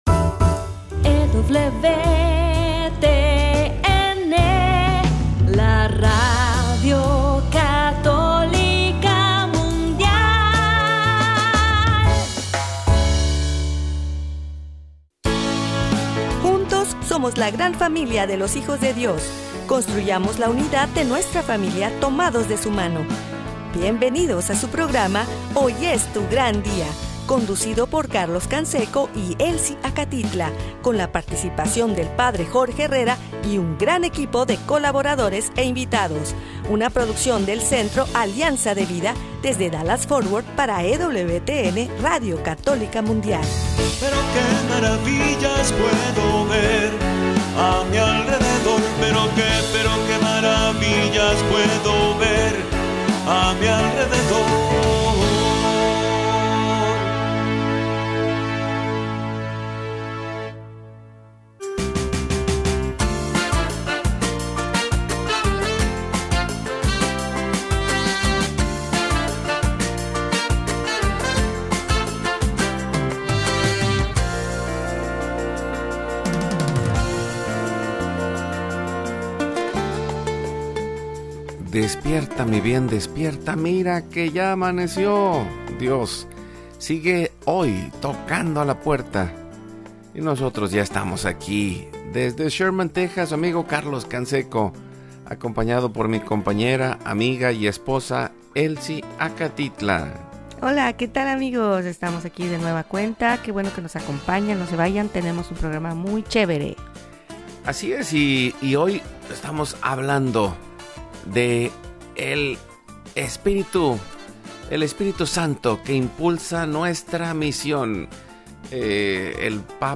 Hoy es tu gran día es un programa de evangelización en vivo desde Dallas, Texas, para vivir en plenitud la vida en Cristo, caminando junto a El para ver las maravillas a nuestro alrededor en la vida diaria como discípulos.